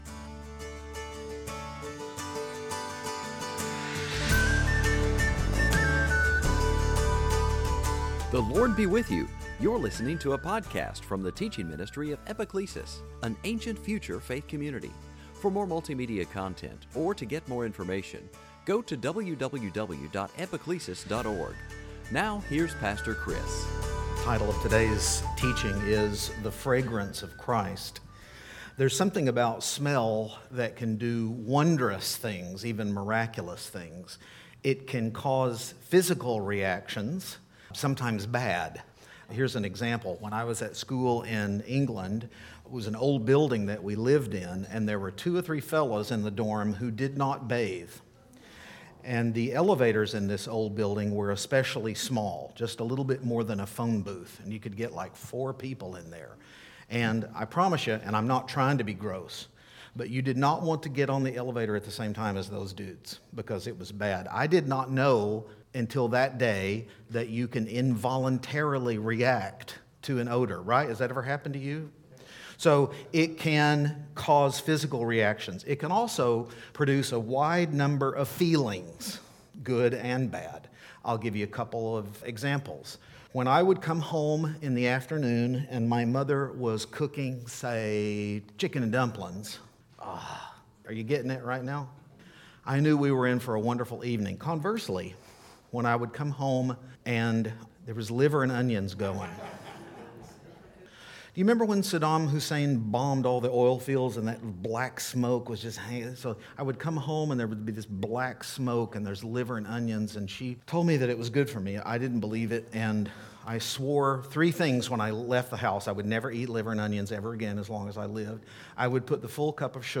Note: The image associated with this podcast/sermon is a detail from the painting titled “Mary Washing Jesus’ Feet” by Lars Justinen.